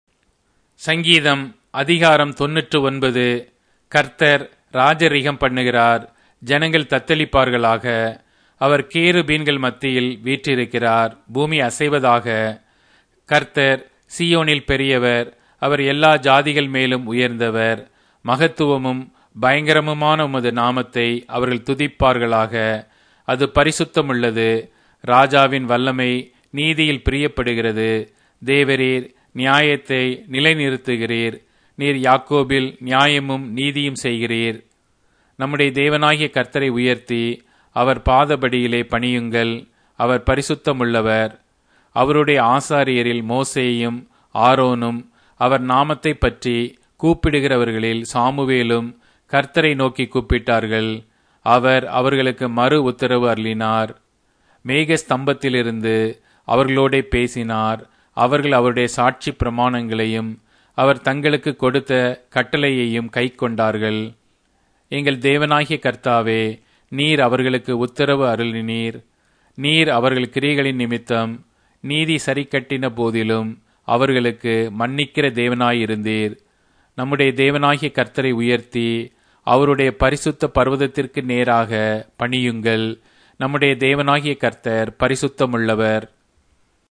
Tamil Audio Bible - Psalms 54 in Rv bible version